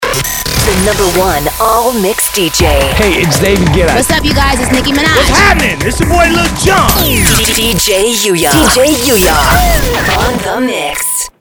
・アーティストIDジングル（2万5千円～）